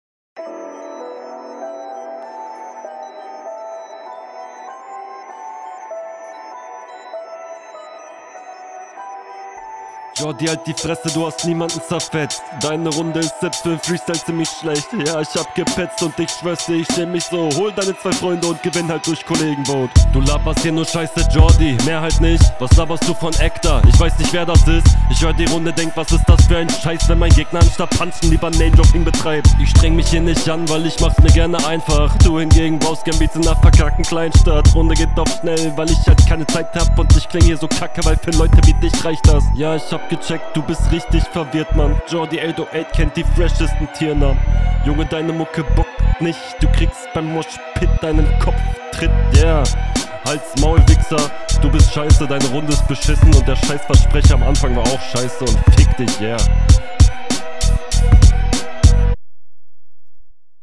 Mische deiner Stimme ist mir allerdings zu dumpf...